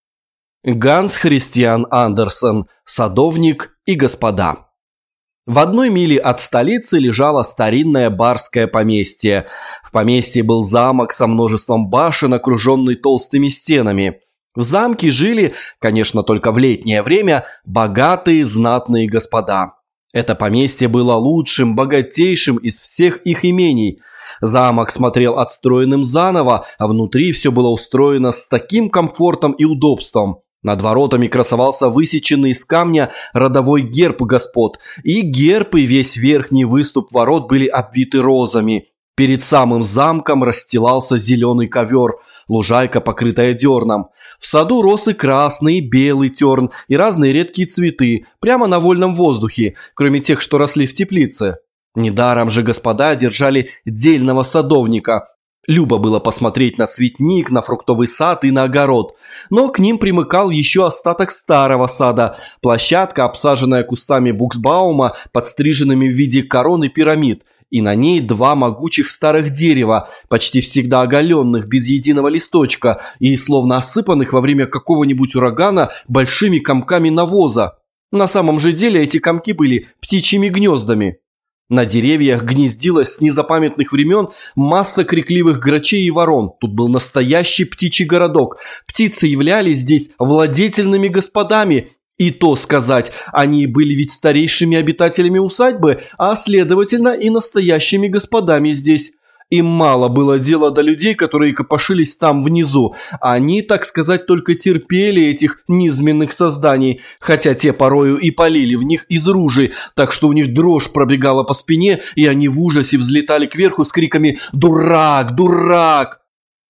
Аудиокнига Садовник и господа | Библиотека аудиокниг
Прослушать и бесплатно скачать фрагмент аудиокниги